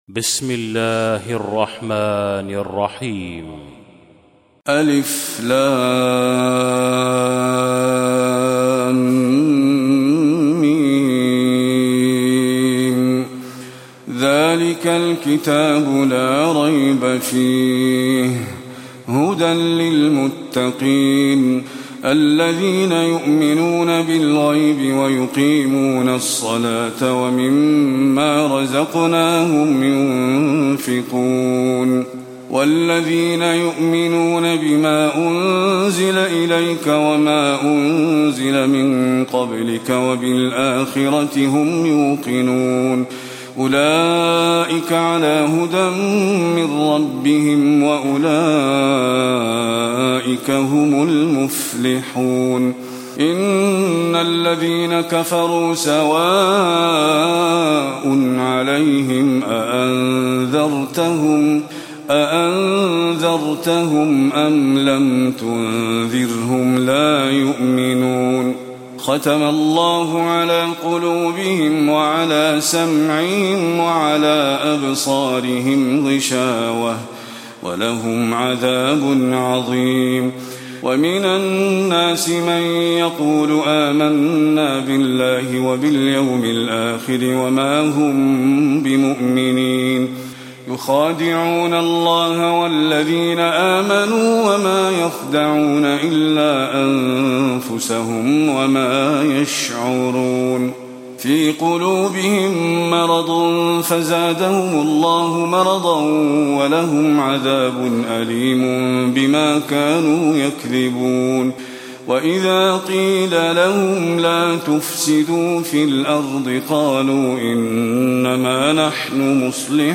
تلاوة سورة البقرة من آية 1 آية 91
المكان: المسجد النبوي